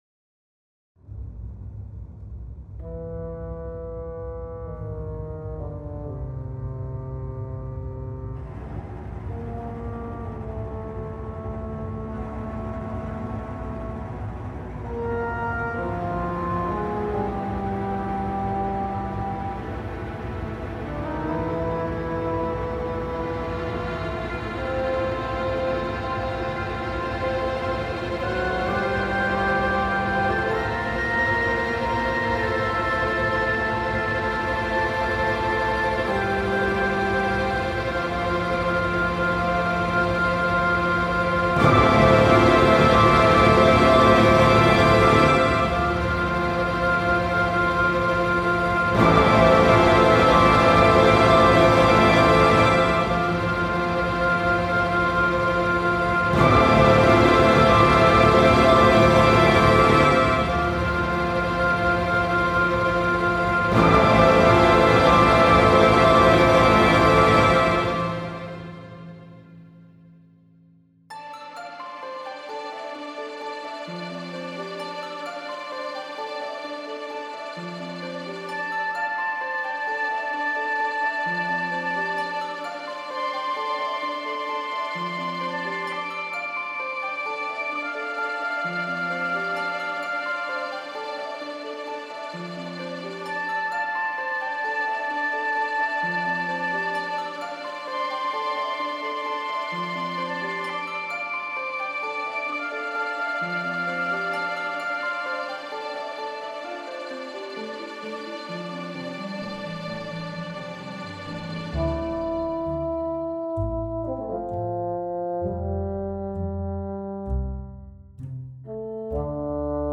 This is a piece for orchestra, with a prominent solo Violin part, similar to Danse Macabre. The story is a journey through tales of fairy and monsters in the woods, with the violin acting as a Puckesque narrator.